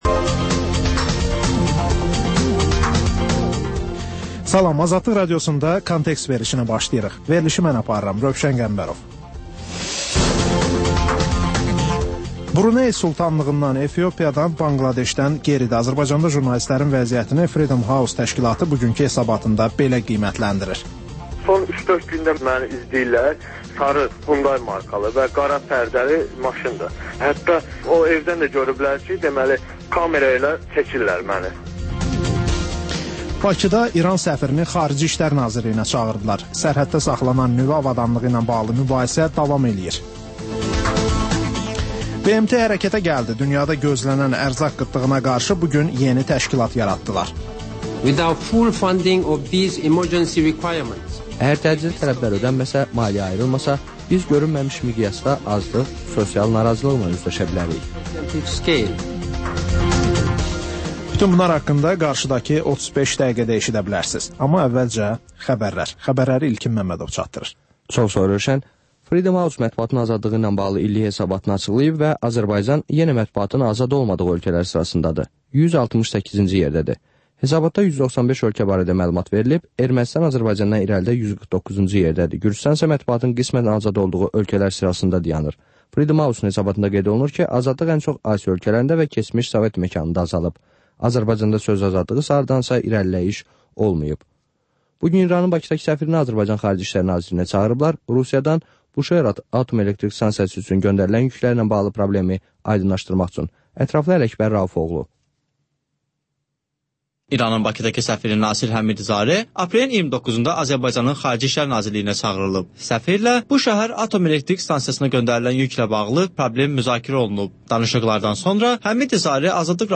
Xəbərlər, müsahibələr, hadisələrin müzakirəsi, təhlillər, sonda ŞƏFFAFLIQ: Korrupsiya haqqında xüsusi veriliş.